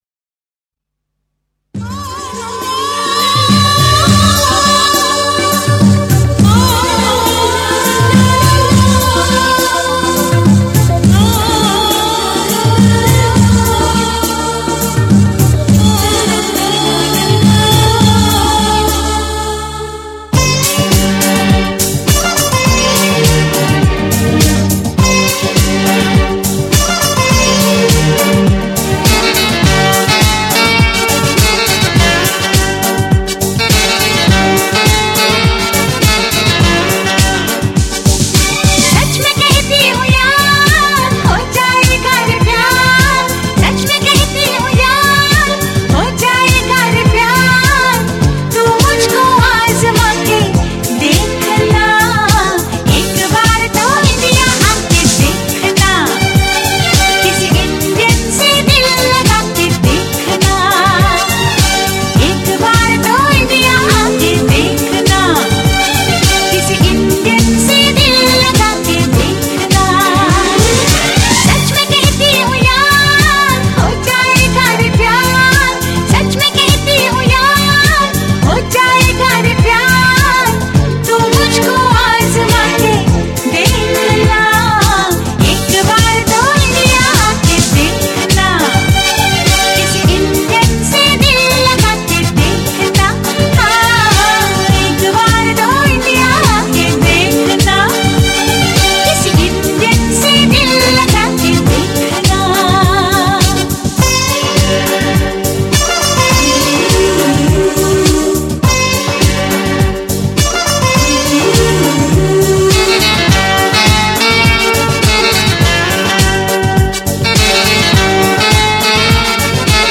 Bollywood Mp3 Songs